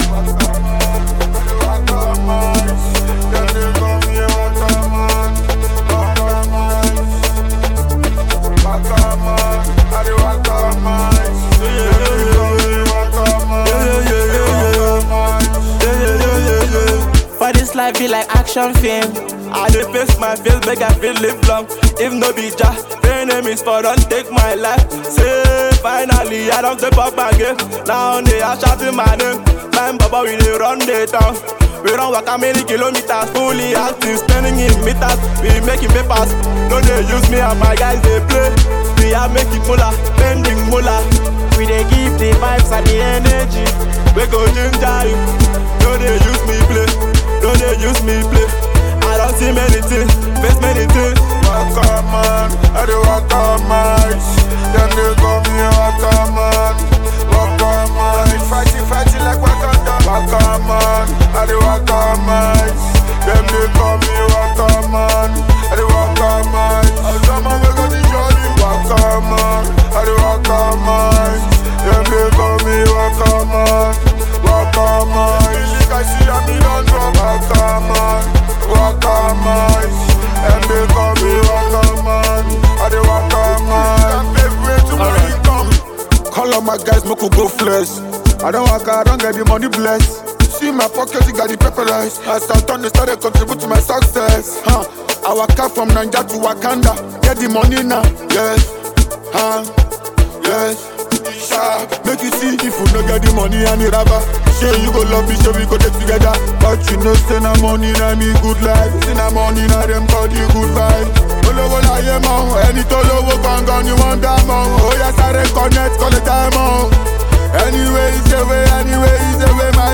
The song’s infectious beat
complementary vocals